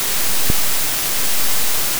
J’ai modélisé le flinch comme un oscillateur harmonique amorti.
La fréquence fondamentale est de 220 Hz (La3).
Lorsque le « flinch » est déclenché, vous entendez le système lutter pour revenir à l’équilibre. C’est une vibration métallique et granulaire enfouie dans un fond de dérive basse fréquence et de pics stochastiques.